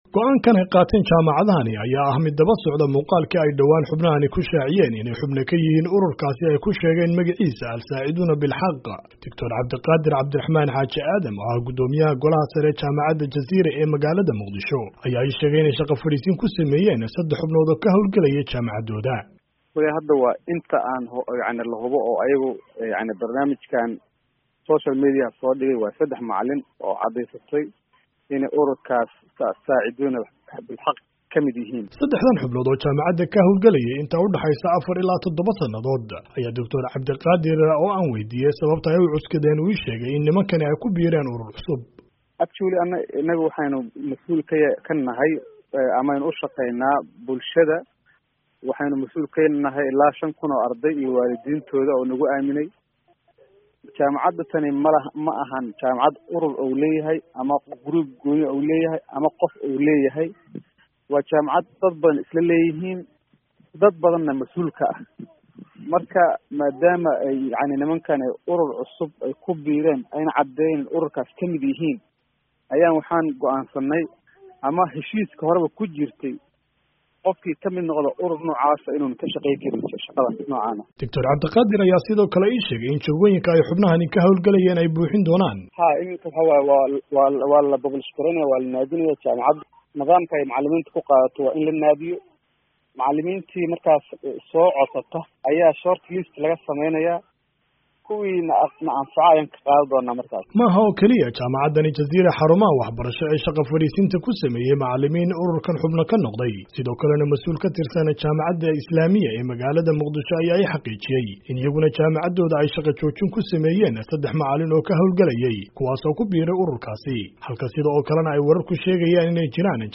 Warbixin